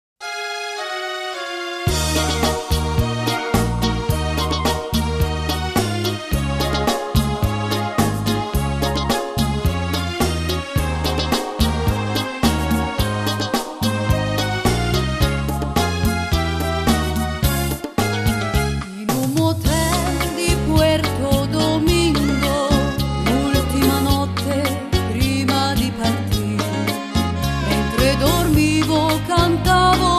Cha cha cha